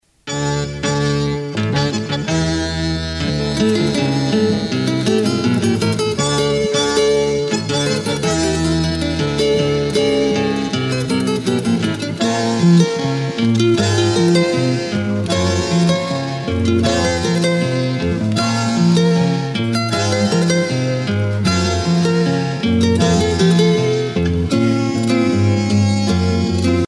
danse : tango (Argentine, Uruguay)